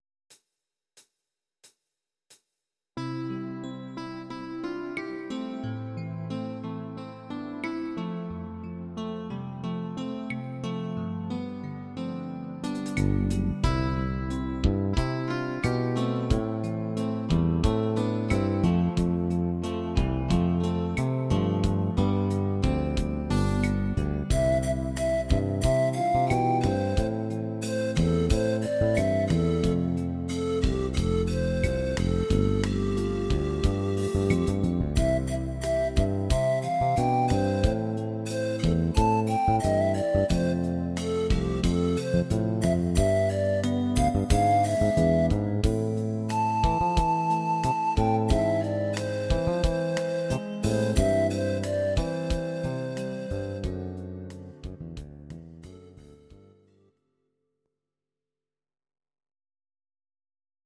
cover
Audio Recordings based on Midi-files
Pop, 1970s